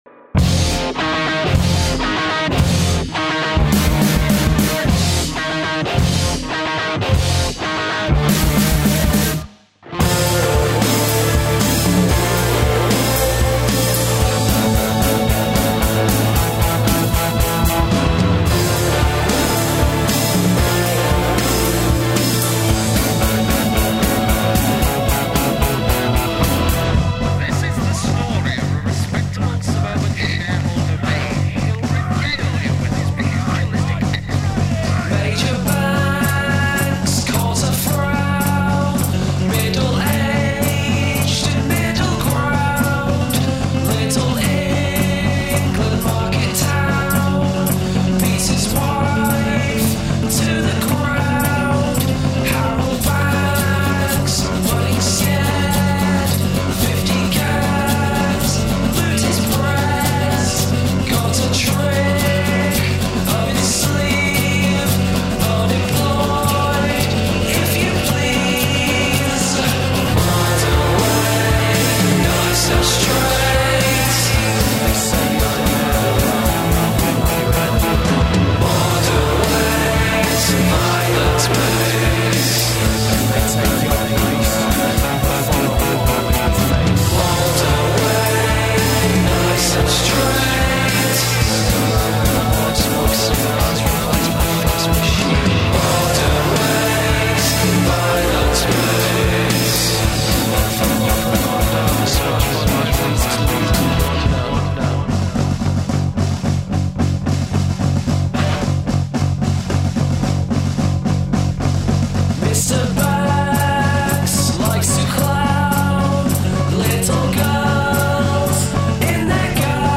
I for one welcome our new Prog Rock Overlords!
guitar/vocals
keyboards/vocals
bass/vocals
drums
create symphonies with eclectic structures